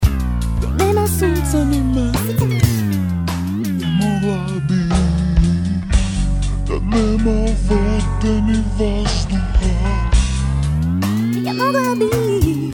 Sa Pitch bender-om